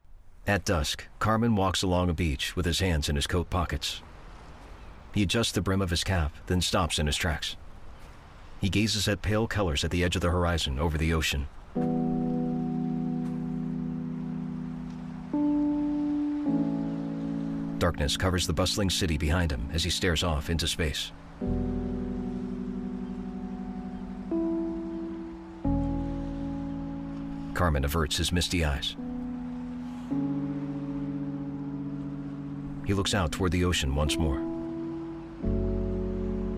Audio Description, Featured Client